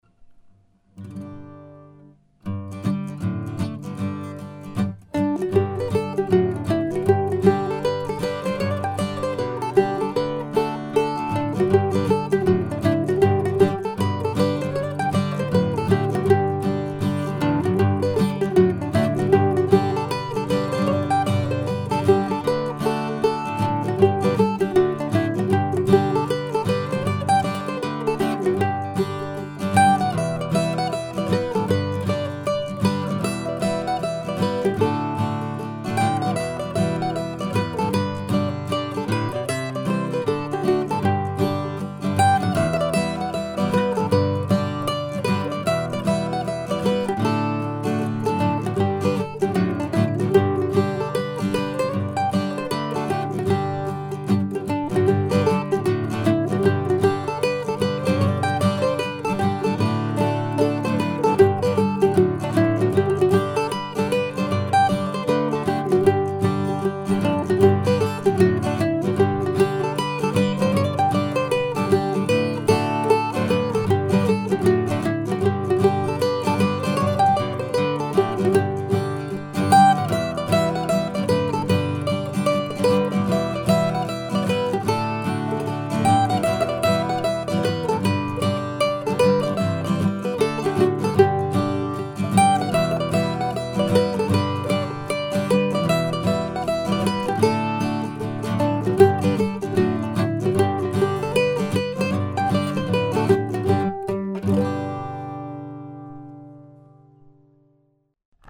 hornpipe
schottische